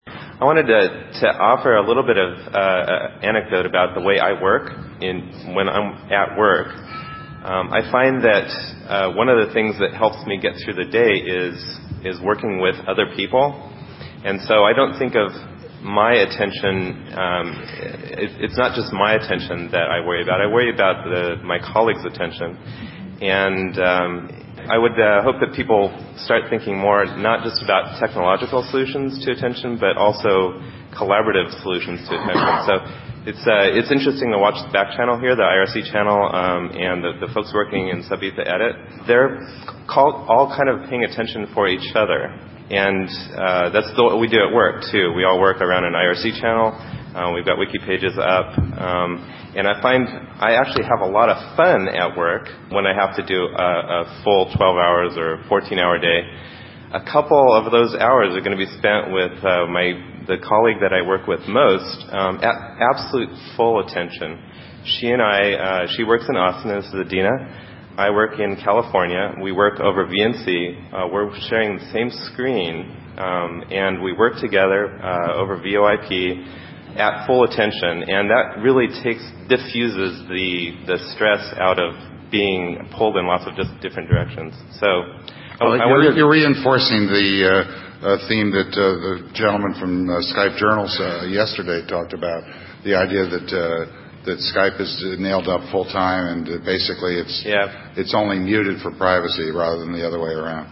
(The recorded comment was made by an audience member at a panel discussion on attention).